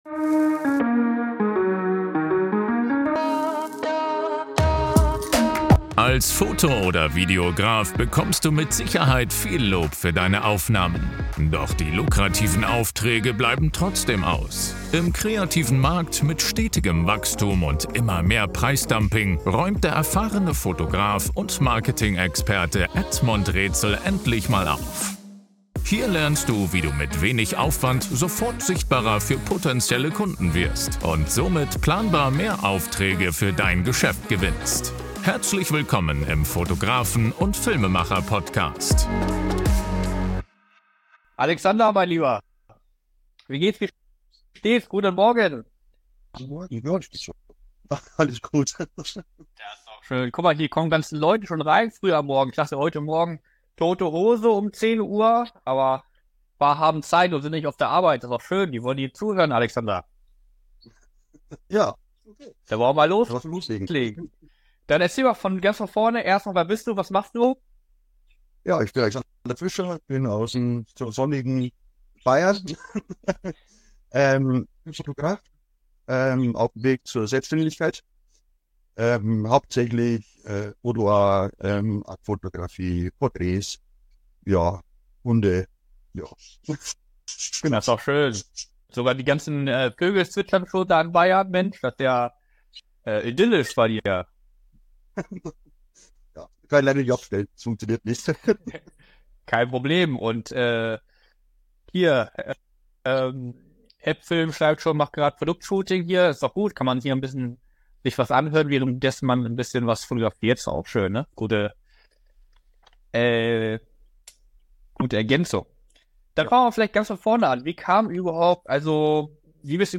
In diesem spannenden Interview teilt er seine hilfreichsten Tipps für Akt Shootings, erzählt von seiner schlimmsten Erfahrung und seinem Weg in die Selbstständigkeit.